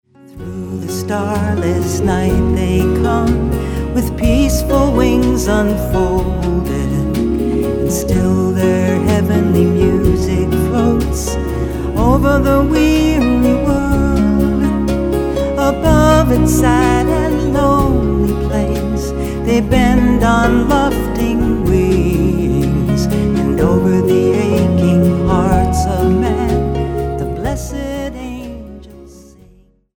Schlagzeug
Perkussion